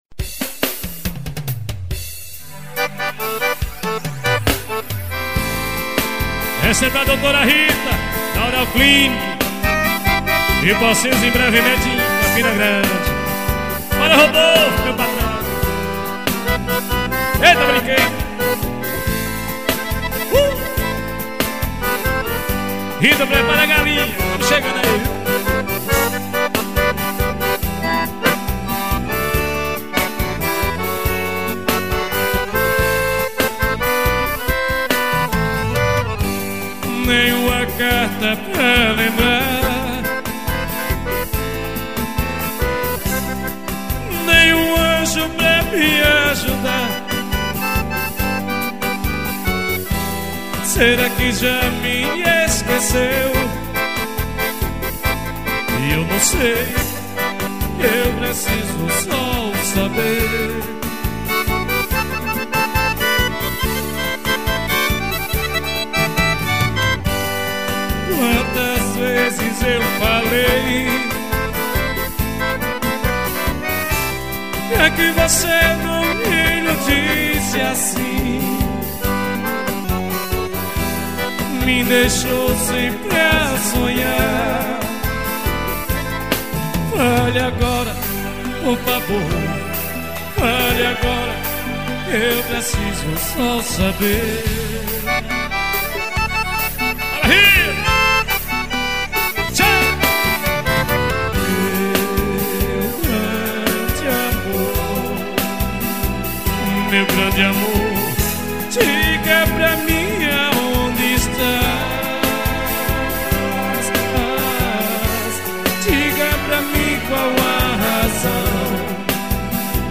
gravação de cd ao vivo.